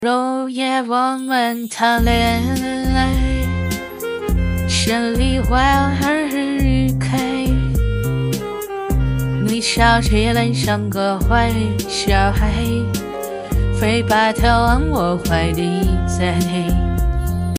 自己训练的大姐姐模型，偏向于东北豪爽大姐姐，说话可以稍微豪放一点。
可以更具我提供的音色试听来调整音调，支持唱歌，对稍微一丢丢的口胡也是支持的。
唱歌